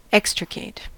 extricate: Wikimedia Commons US English Pronunciations
En-us-extricate.WAV